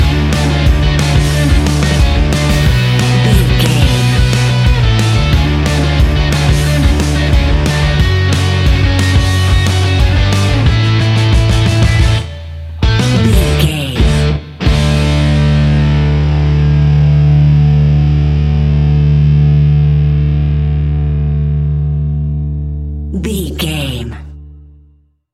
Epic / Action
Fast paced
Ionian/Major
D
hard rock
distortion
punk metal
Rock Bass
heavy drums
distorted guitars
hammond organ